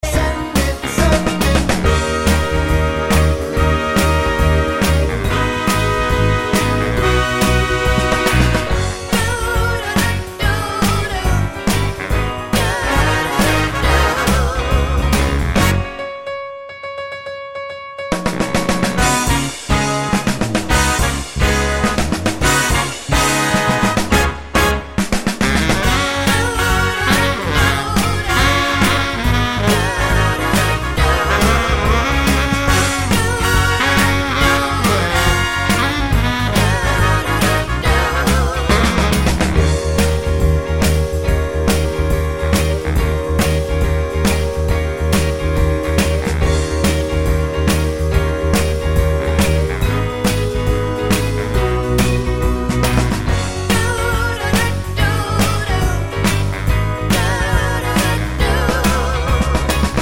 - no Backing Vocals Soul / Motown 2:22 Buy £1.50